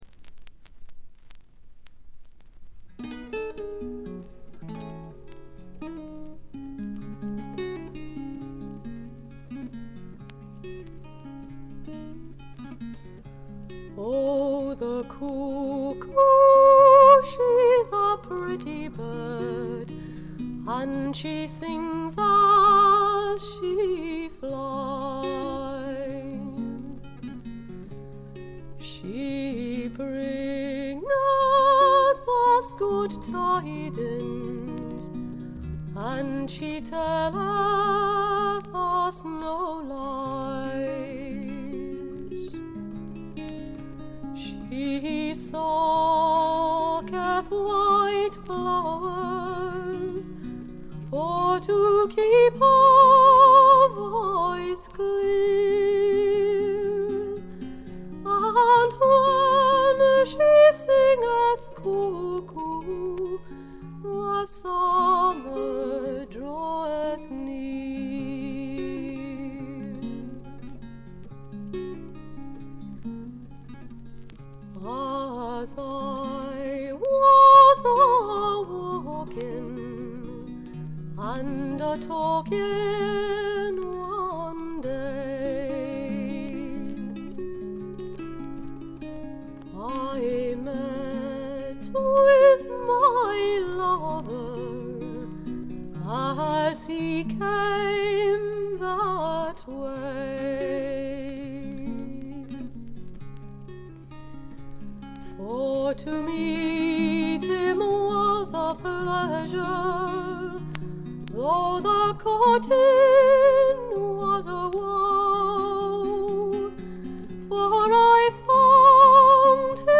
軽微なバックグラウンドノイズ、チリプチ。A1中盤でプツ音1回。
試聴曲は現品からの取り込み音源です。